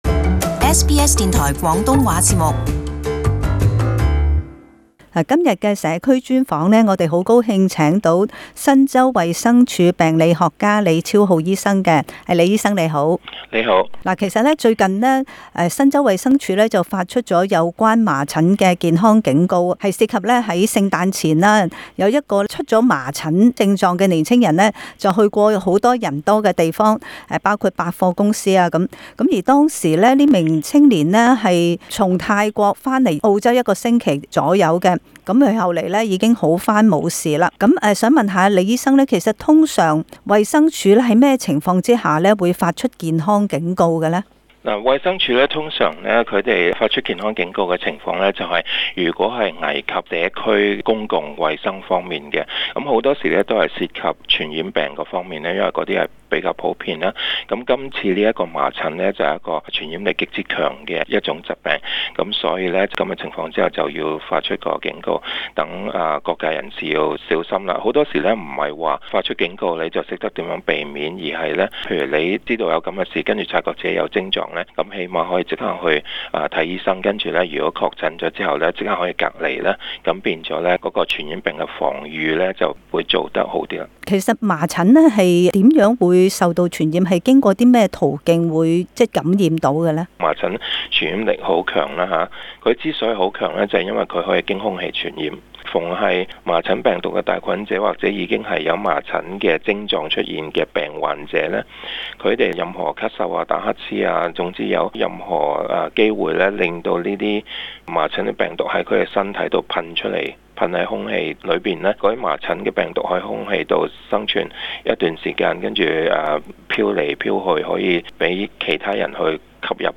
【社區專訪】如何應對麻疹疫情警告？